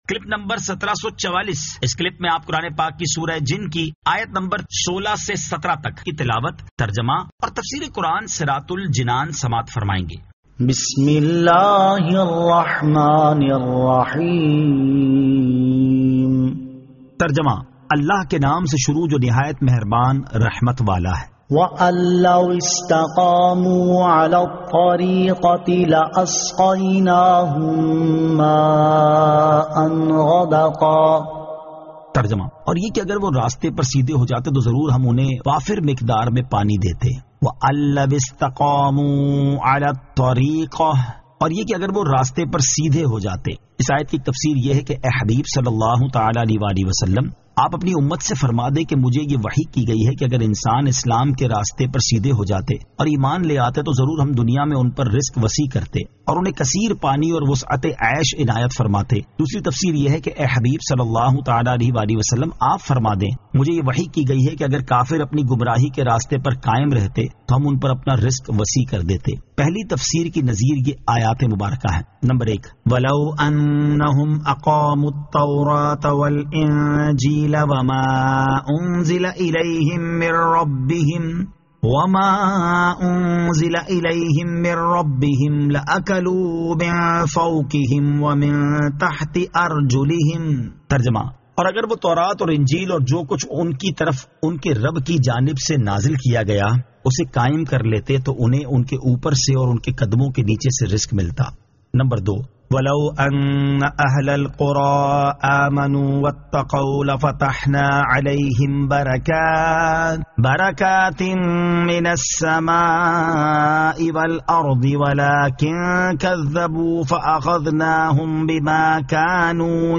Surah Al-Jinn 16 To 17 Tilawat , Tarjama , Tafseer